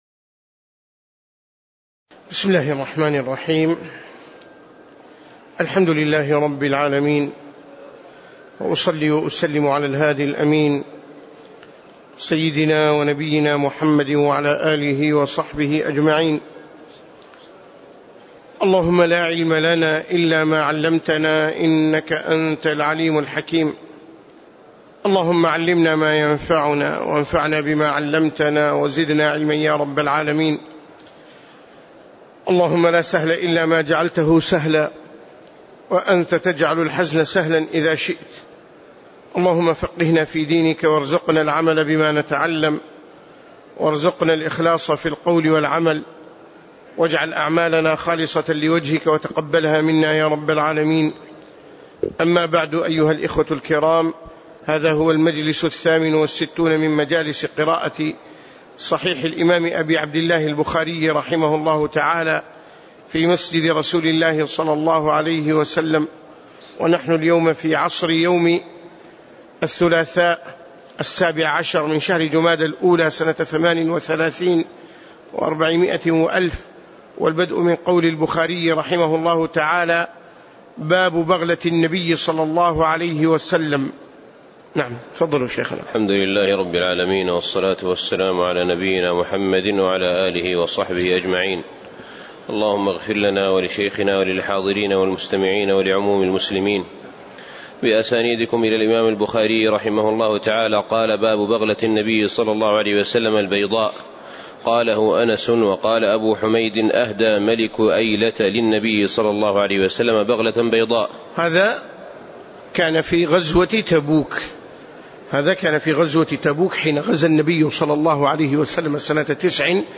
تاريخ النشر ١٧ جمادى الأولى ١٤٣٨ هـ المكان: المسجد النبوي الشيخ